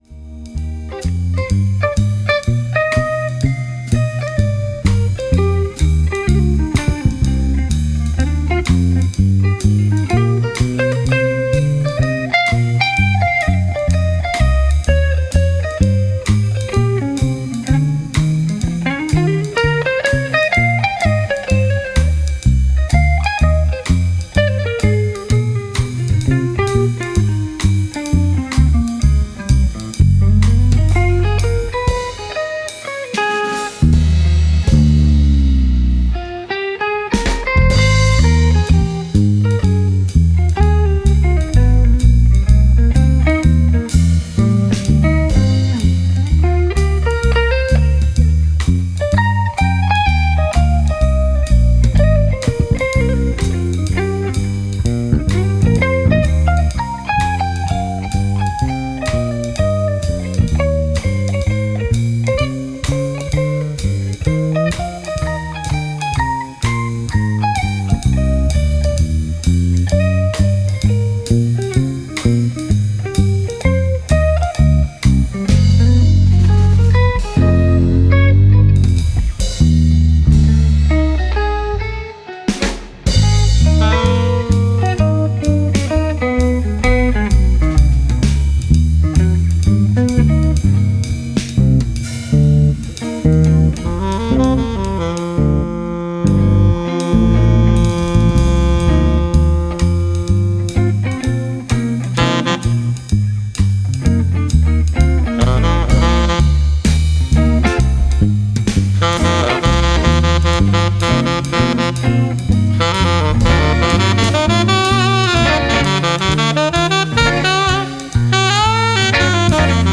Recorded in studio